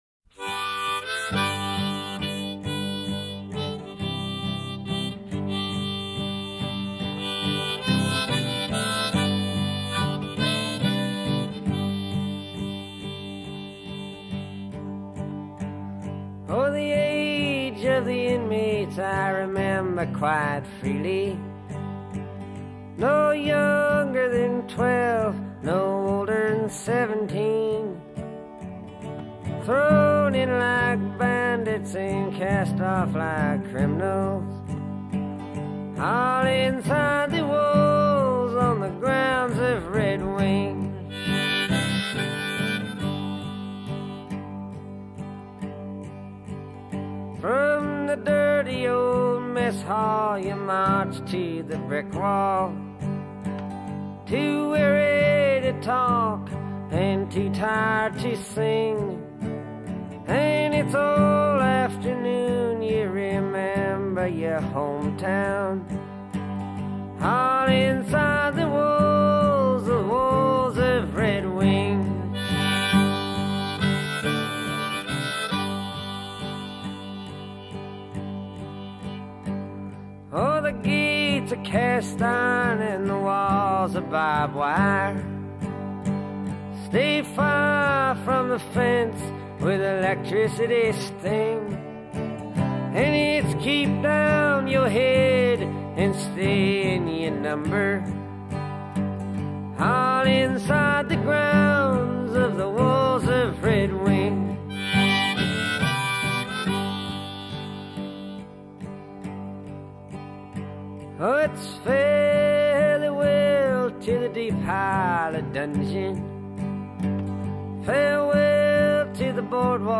traditional folk